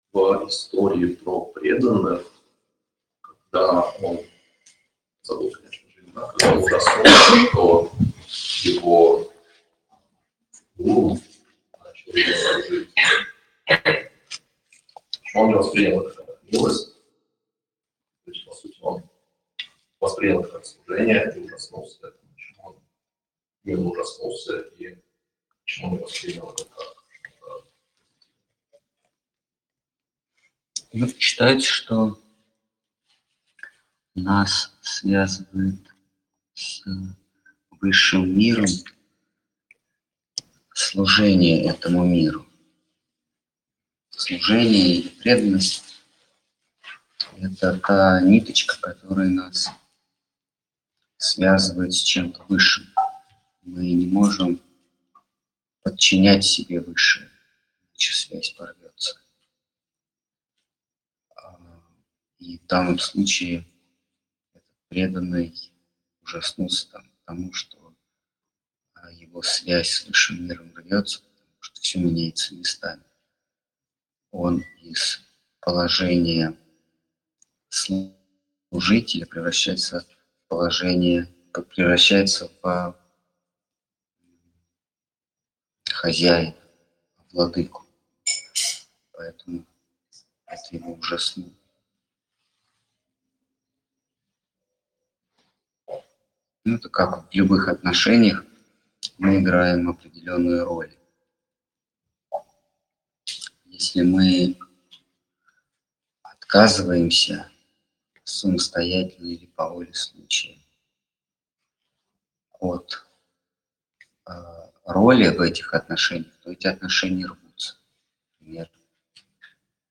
Ответы на вопросы из трансляции в телеграм канале «Колесница Джаганнатха». Тема трансляции: Слово Хранителя Преданности.